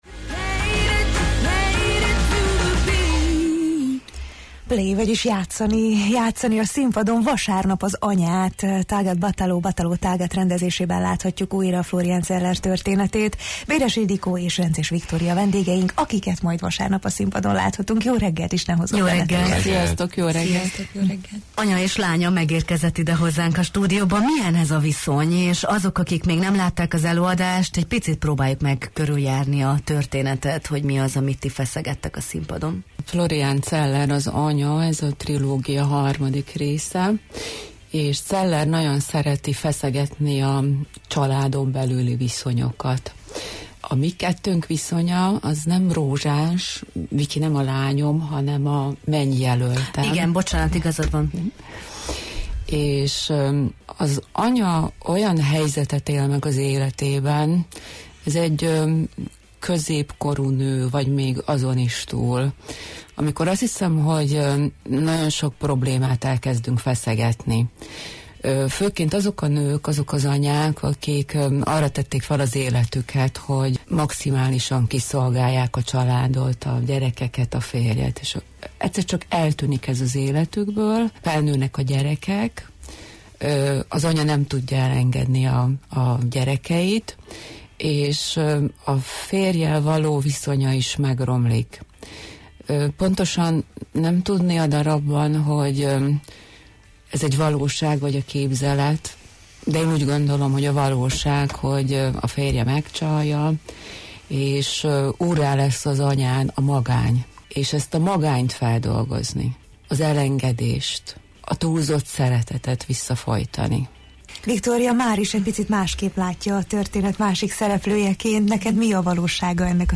a Jó reggelt, Erdély! című műsorban is meséltek az előadásról: